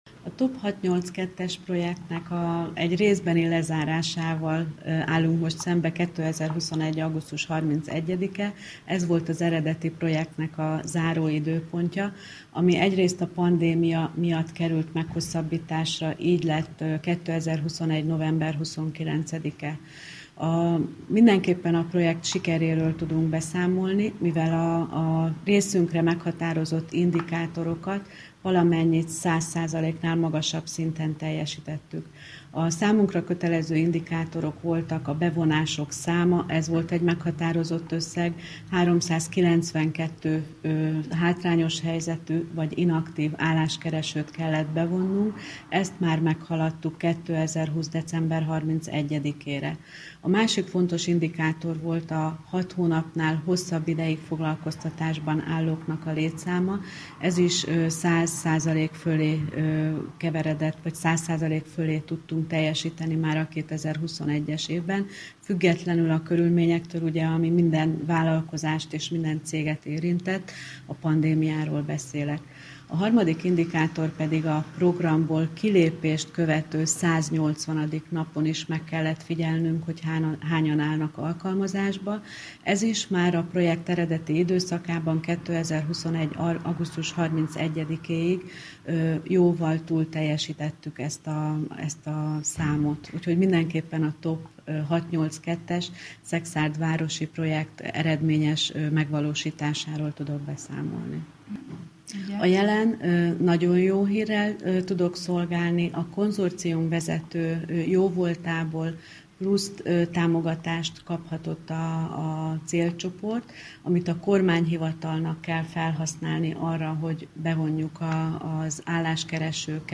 Ezúttal kifejezetten szekszárdiaknak. 2017-ben indult az a program, mely a hátrányos helyzetű, munkát nehezen találók segítését szolgálta. A részletekről sajtótájékoztatón számoltak be.